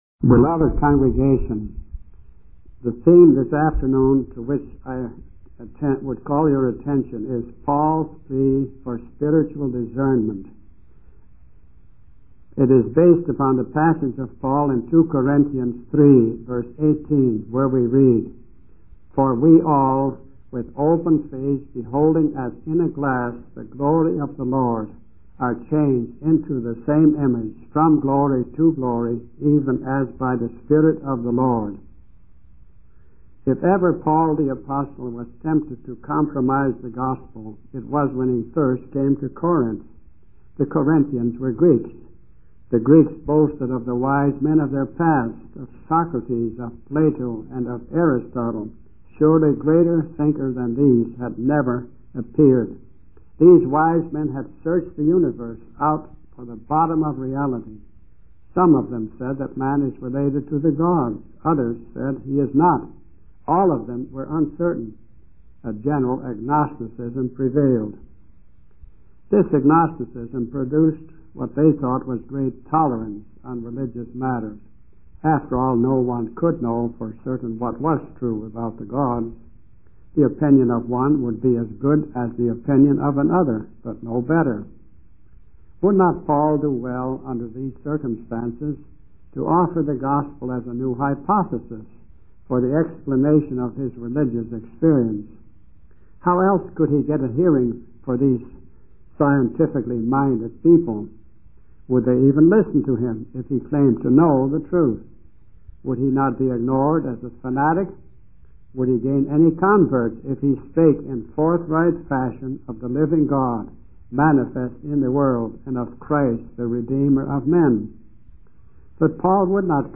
In this sermon, the preacher emphasizes the importance of spreading the light of the gospel to those who are still in darkness. He warns against false teachers and encourages the listeners to stay true to the truth they have come to know. The preacher draws parallels to Moses and the words of Jehovah, highlighting the significance of obedience and the promise of being a holy nation.